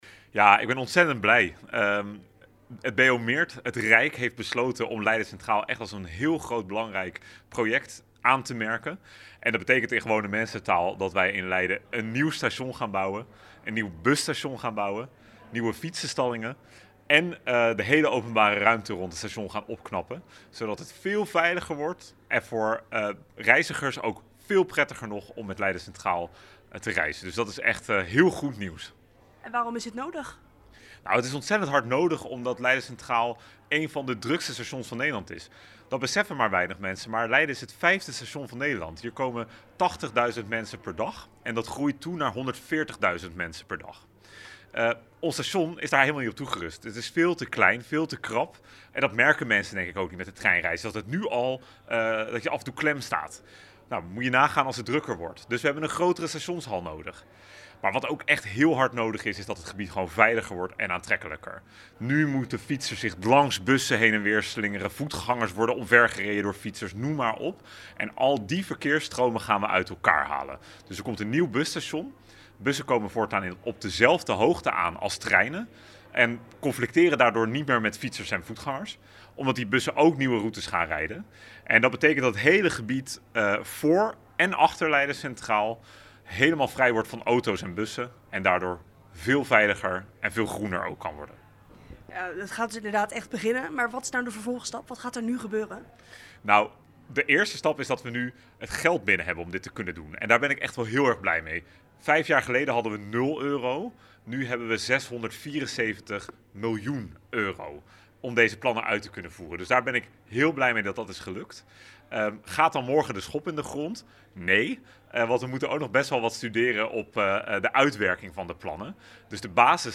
Wethouder Ashley North over het nieuwe station in Leiden: